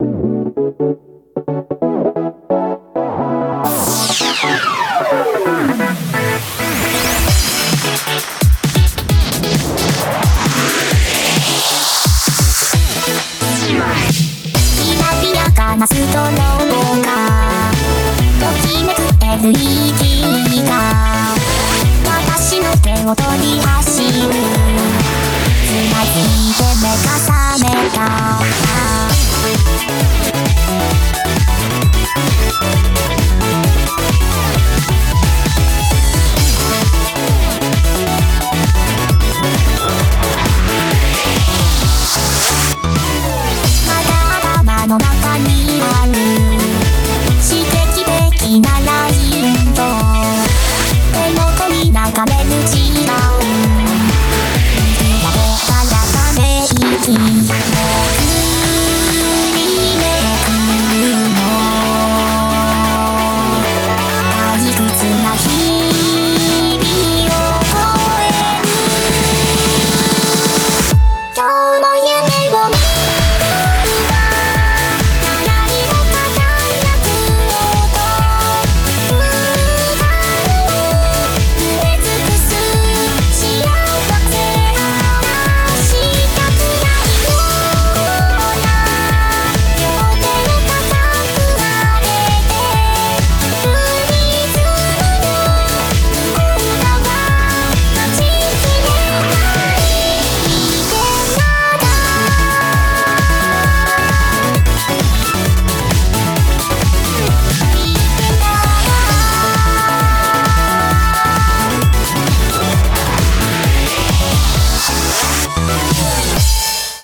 BPM132